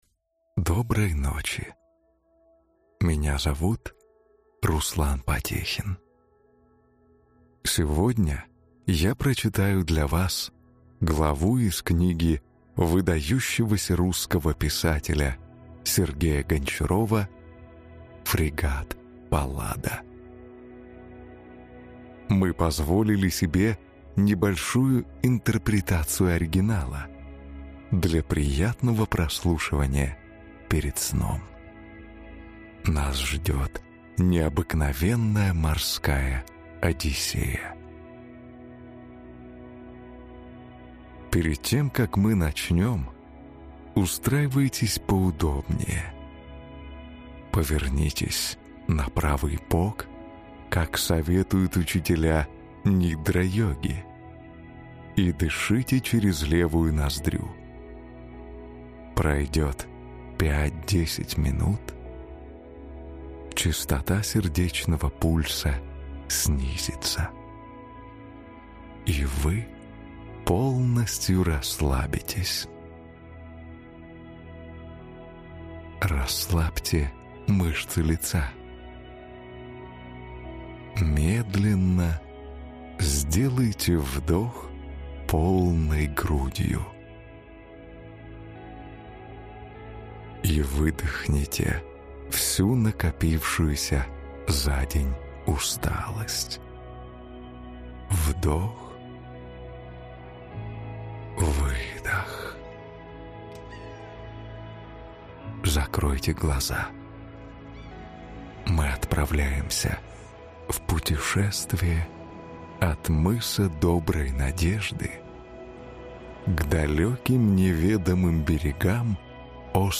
Aудиокнига Фрегат «Паллада»